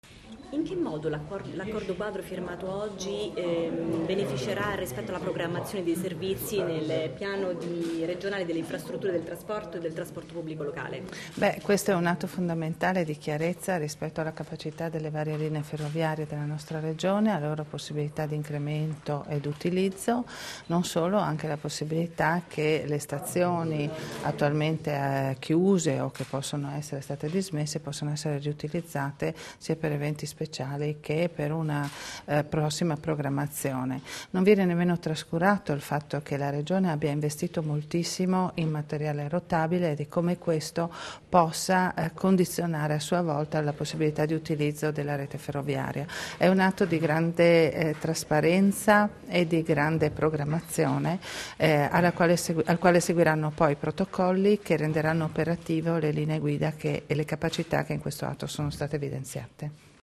Dichiarazioni di Mariagrazia Santoro (Formato MP3)
a margine della firma dell'Accordo Quadro tra Regione FVG e Rete Ferroviaria Italiana (RFI), rilasciate a Roma il 3 febbraio 2016